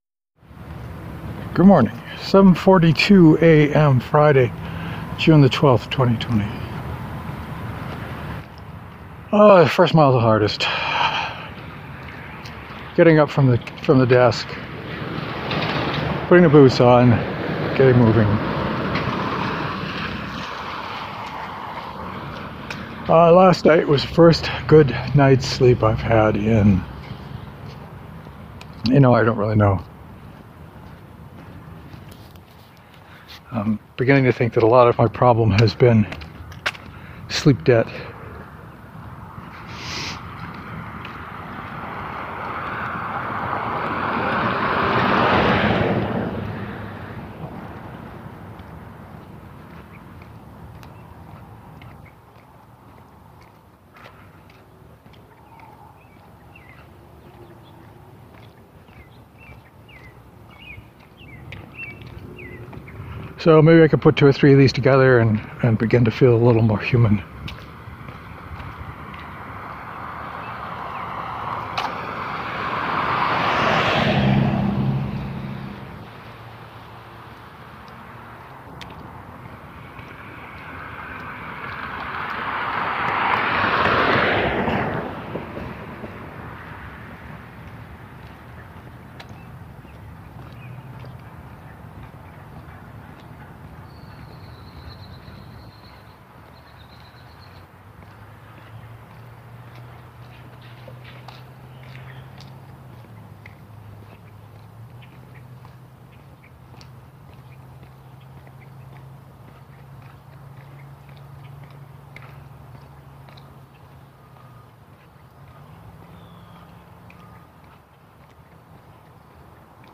I didn’t have much to talk about today but I got the walk out. Lots of boot sounds. Not much talking.